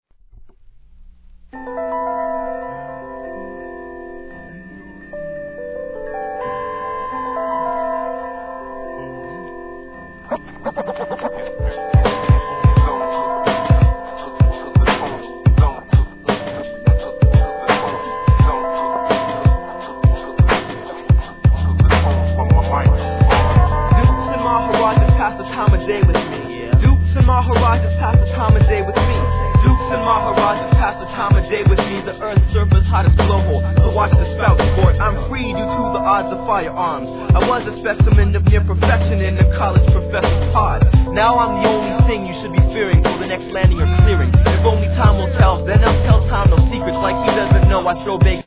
HIP HOP/R&B
浮遊感あるDOPEトラックでのMICリレー!!